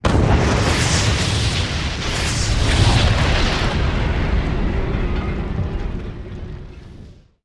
Explosions; Multiple 01